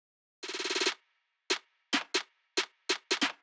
drill roll [140 bpm].wav